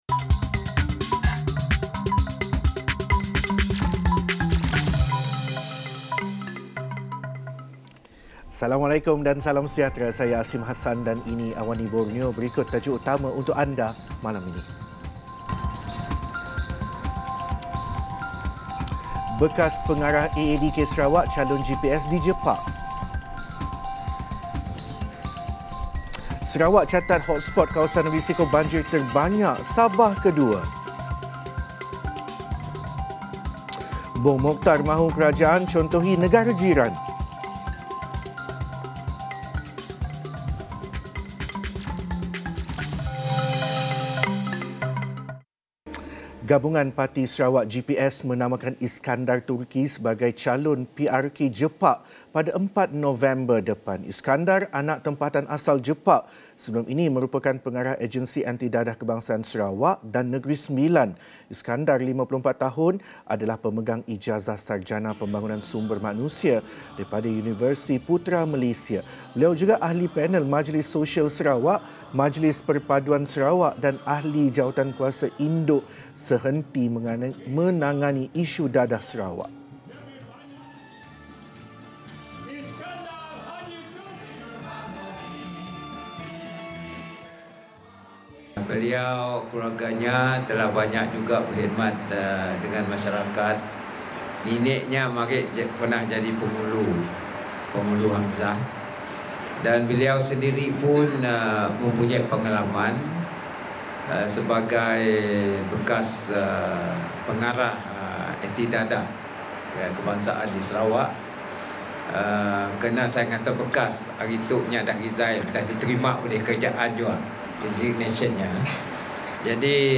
Laporan berita padat dan ringkas dari Borneo